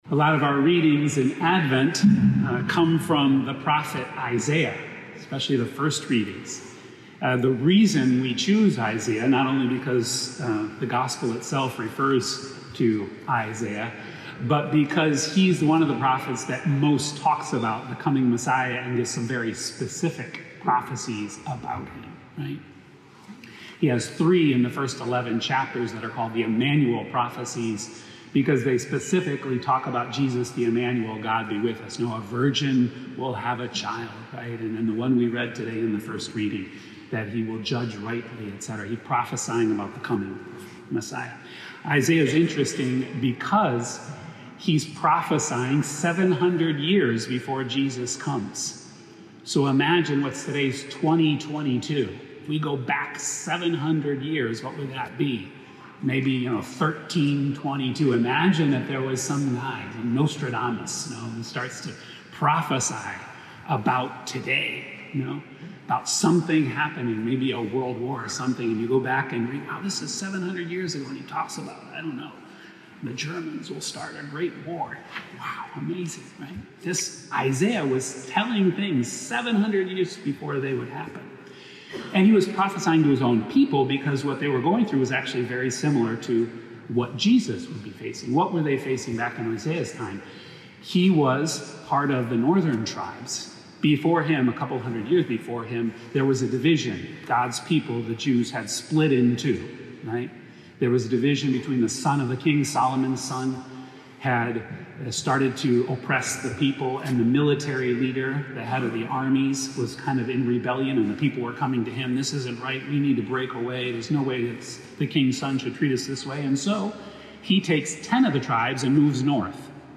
Second Sunday of Advent Homily